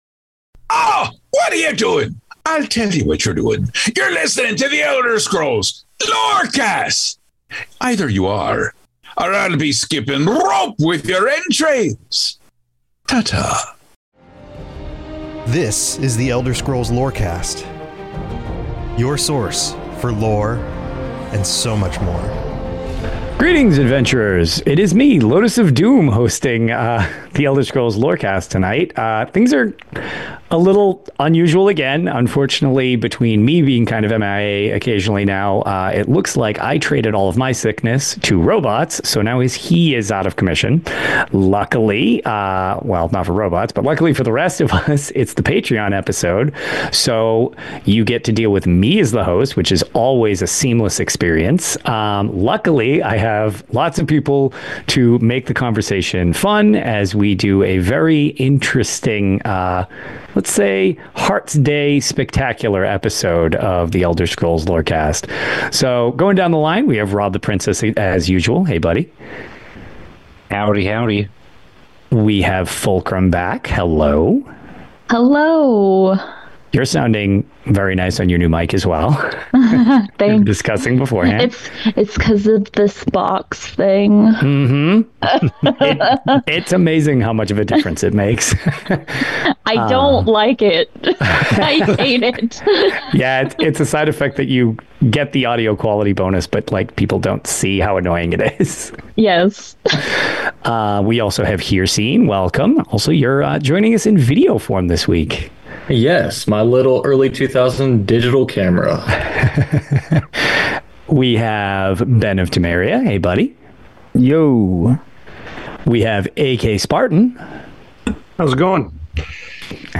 The Elder Scrolls universe explained beginning with perfect bite-sized chunks and evolving into a weekly conversation.